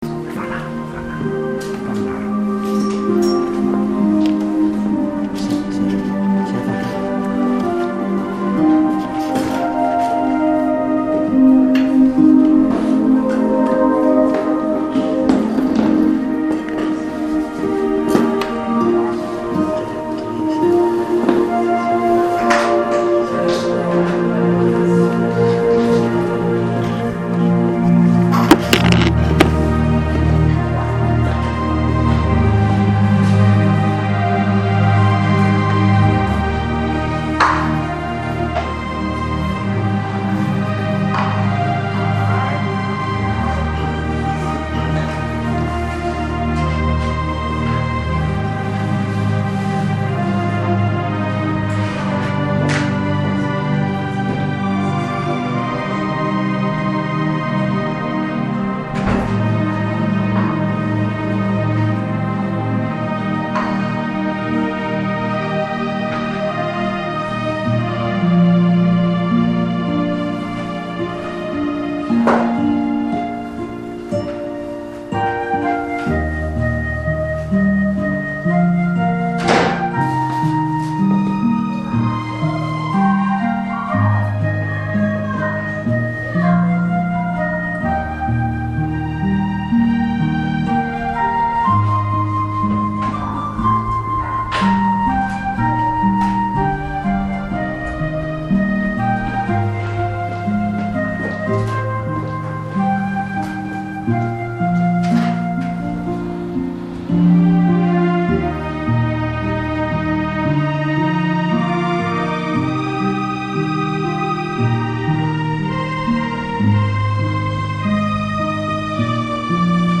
正在播放：--主日恩膏聚会录音（2014-12-28）